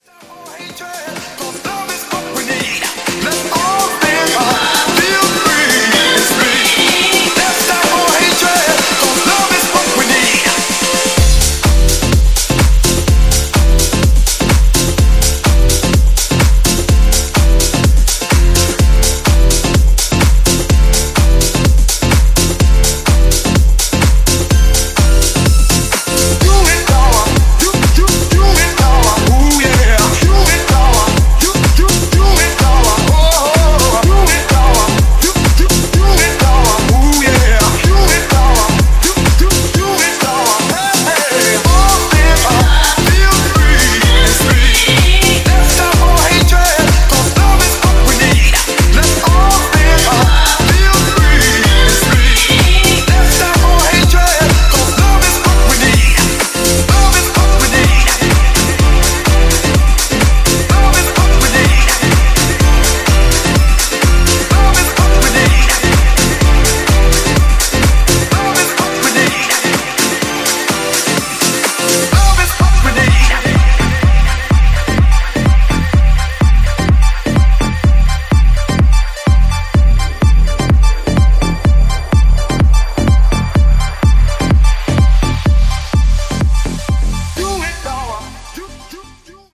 ジャンル(スタイル) DISCO HOUSE